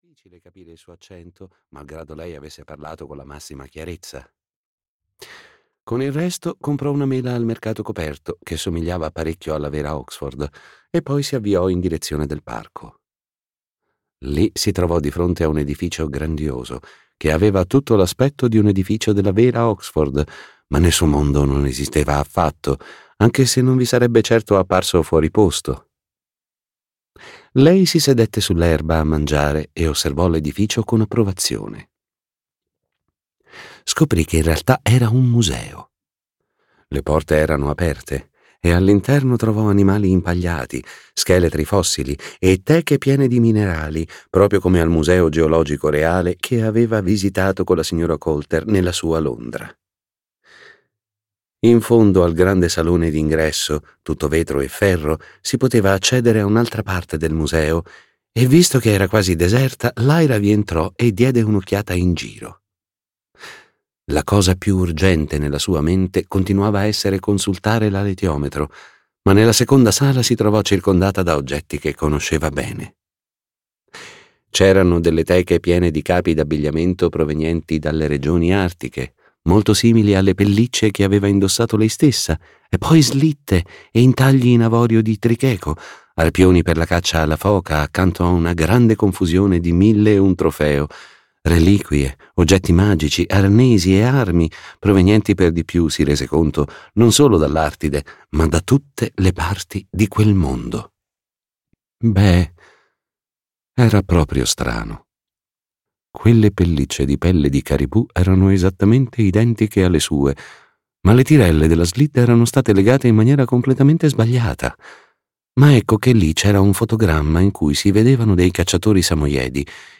"La lama sottile" di Philip Pullman - Audiolibro digitale - AUDIOLIBRI LIQUIDI - Il Libraio